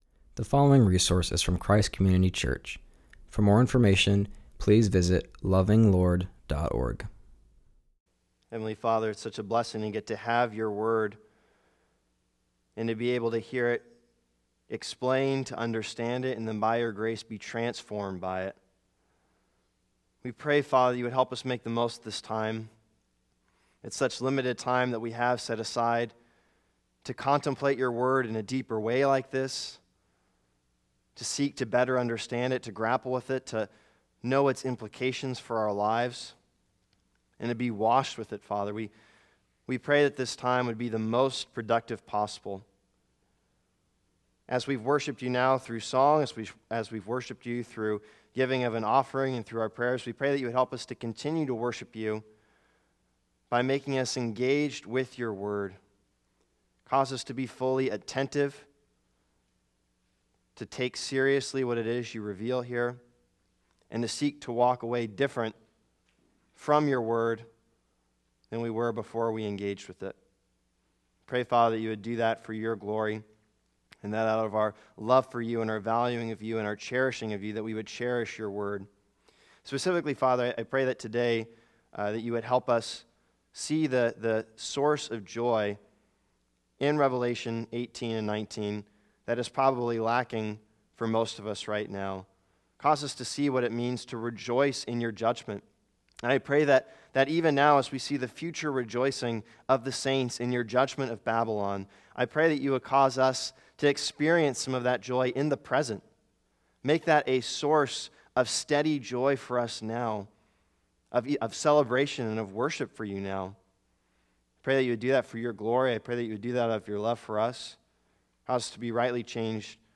continues our study in Revelation by preaching on Revelation 18:20:19:5.